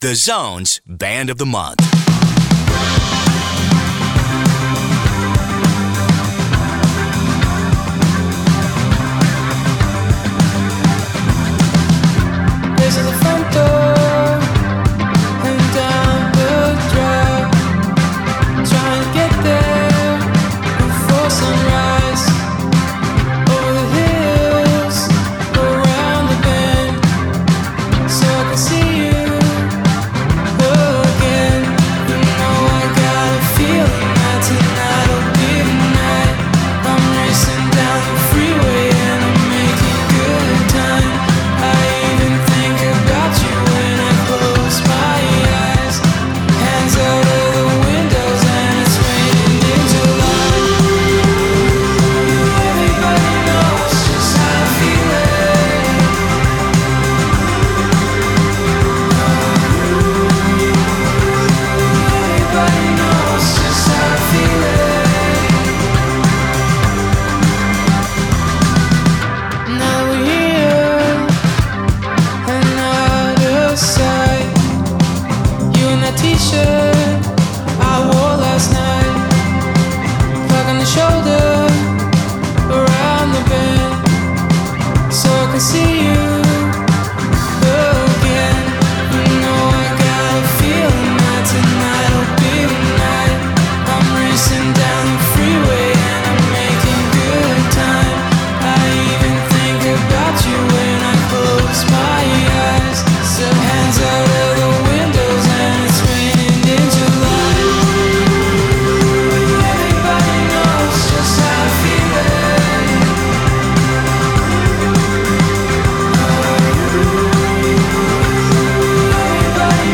Vocals/Guitar
alternative rock duo from Vancouver Island